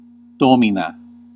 Ääntäminen
RP : IPA : /bɒs/ GenAm: IPA : /bɔs/